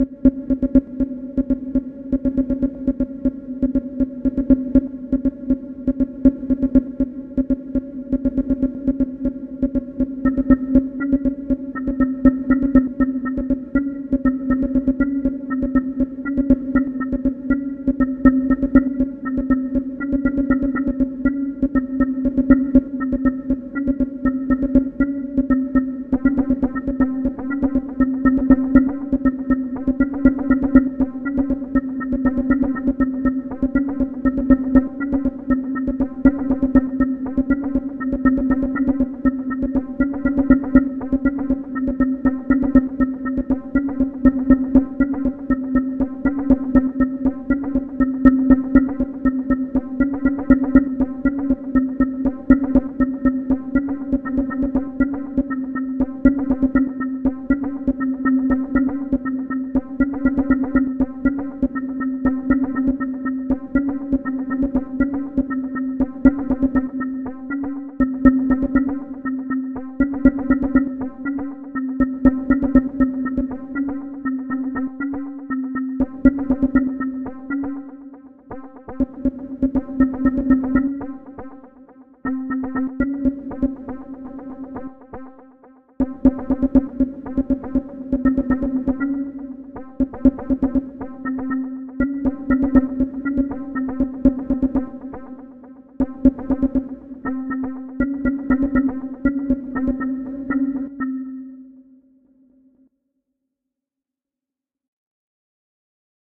Pieza de Intelligent dance music (IDM)
Música electrónica
Dance
sintetizador